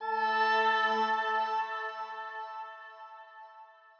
SFX_aura.wav